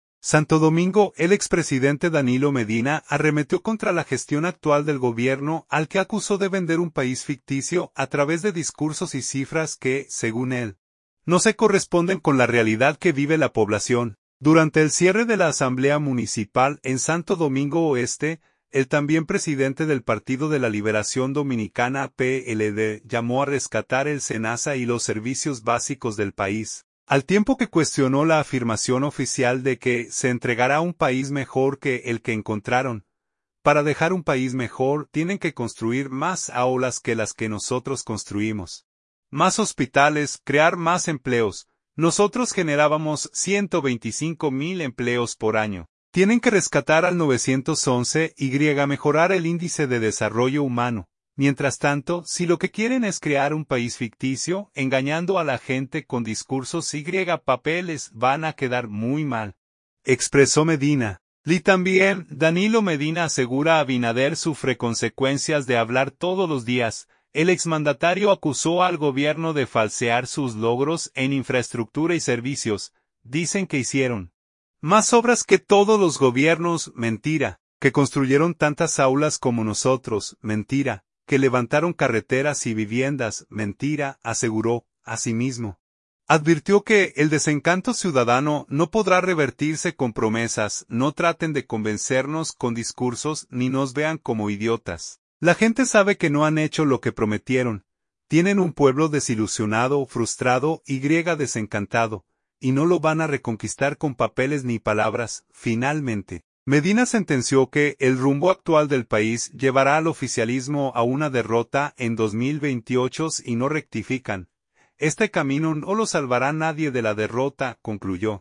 Durante el cierre de la Asamblea Municipal en Santo Domingo Oeste, el también presidente del Partido de la Liberación Dominicana (PLD) llamó a “rescatar el SeNaSa y los servicios básicos del país”, al tiempo que cuestionó la afirmación oficial de que se entregará “un país mejor que el que encontraron”.